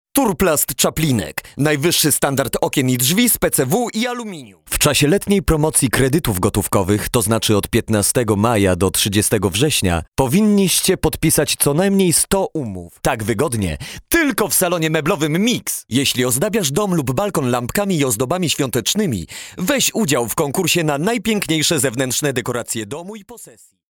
polnischer Sprecher für Werbung, Industrie, Imagefilme
Sprechprobe: Werbung (Muttersprache):
polish voice over talent